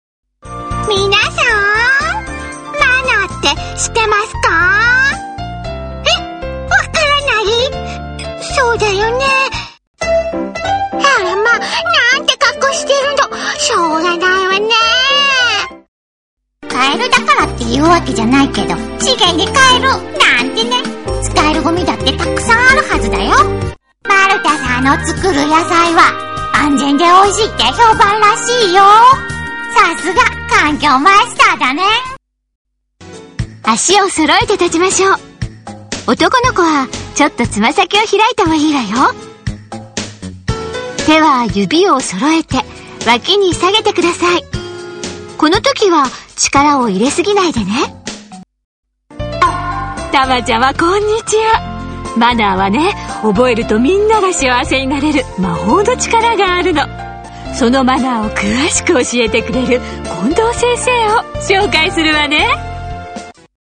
VPナレーション-キャラクター　VPナレーション
《キャラクター》   1 女の子（子供）ナレーション
2 小動物系（ドラえもん風）ナレーション
3 子供ショーのお姉さん風ナレーション
4 おばさま風ナレーション